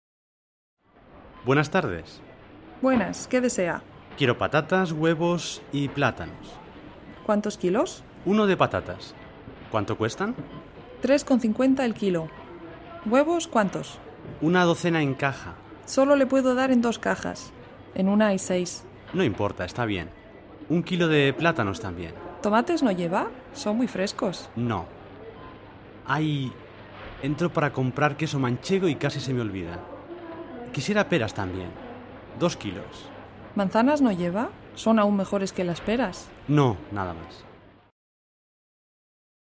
Écoute attentivement le dialogue, plusieurs fois si c'est nécessaire. comprar_co_vof.mp3 1. Compra un kilo de patatas por 3,55 euros. 2.